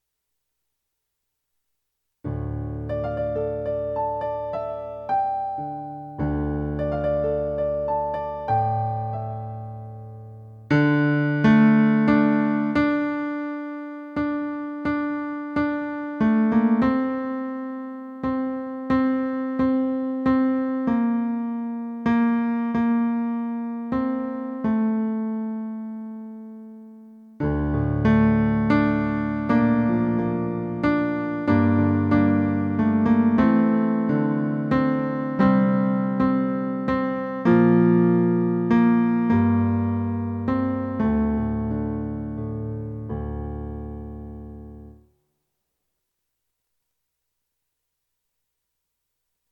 Create In Me - Refrain - Tenor